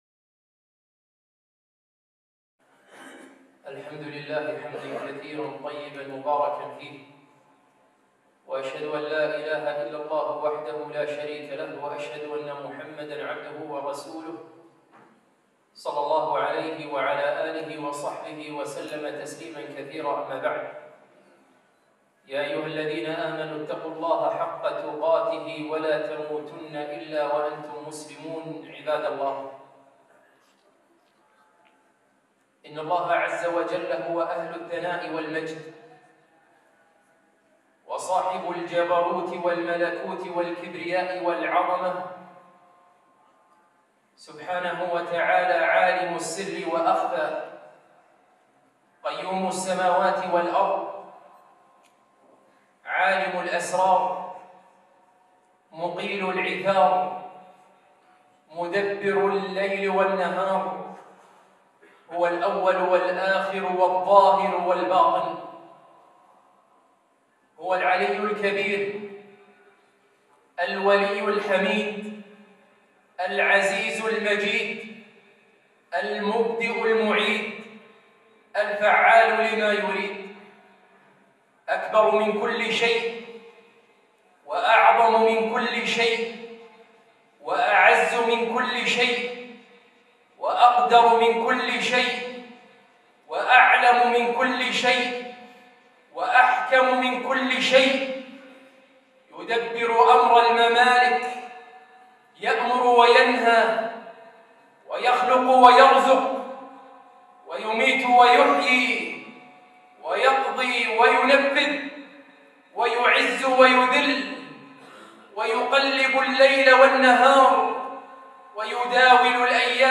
خطبة - تعظيم الله عز وجل